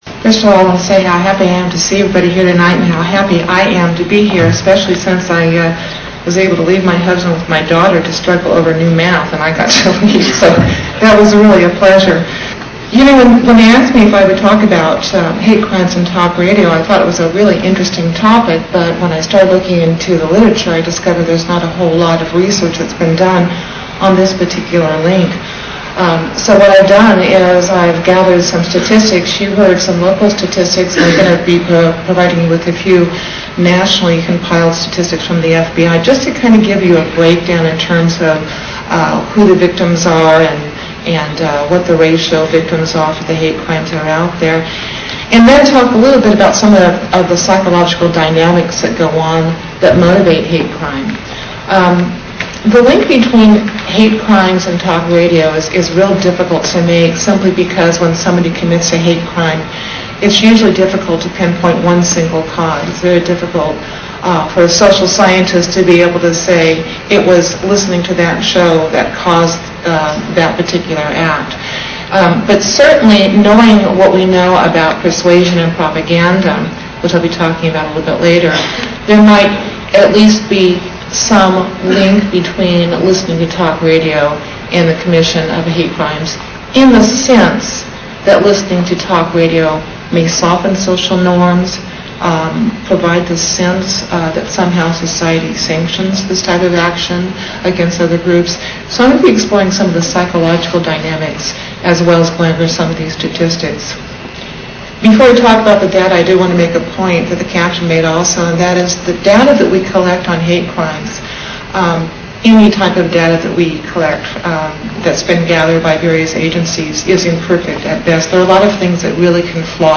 One hundred ninety students and community members attended the forum“Talk Radio And Hate Crimes: Is There A Connection?” on November 15th at Fresno City College.